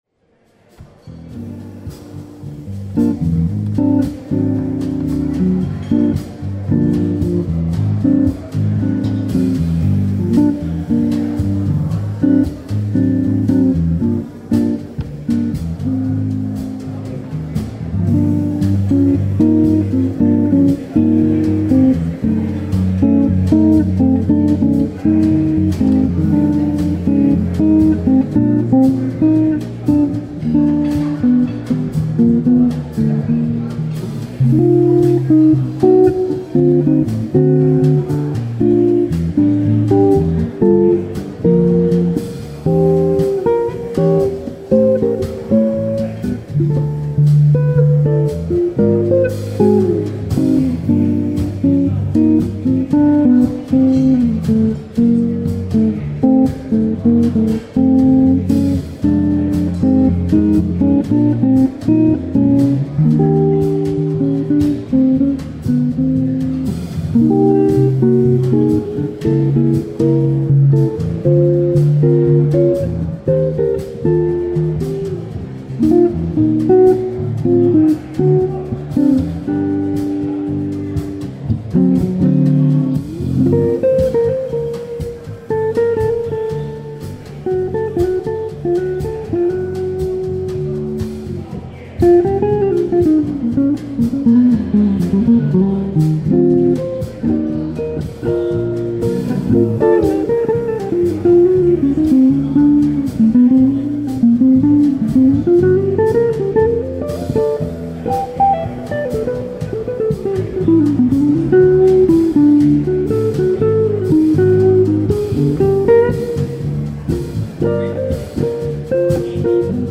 Drumless Trio
guitar
tenor saxophone
bass